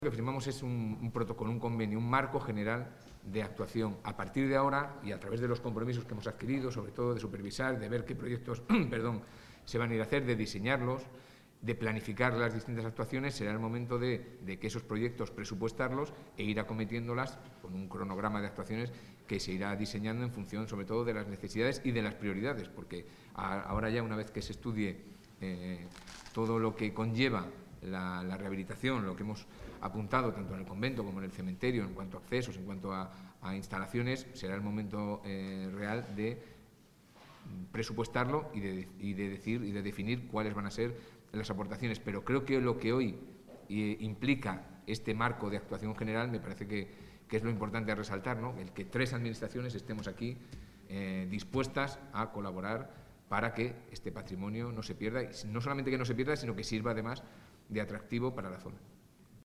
Intervención consejero.